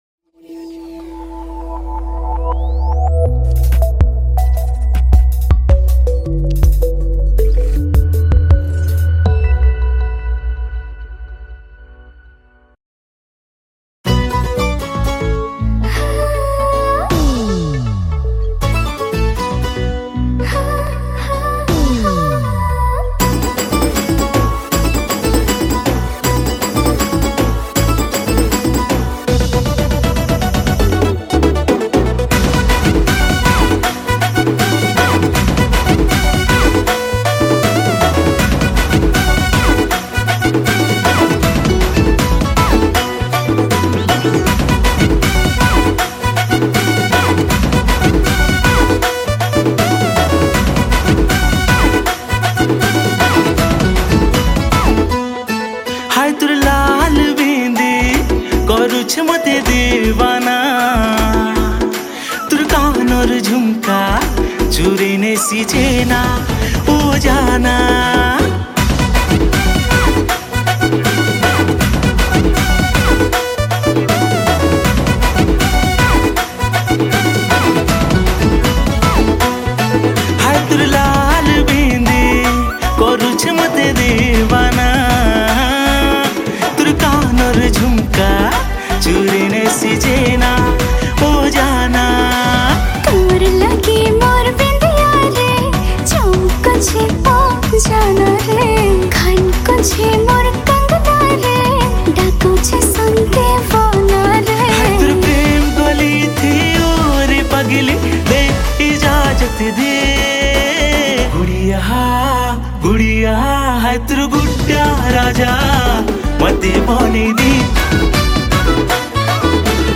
Koraputia Song Songs Download
Drums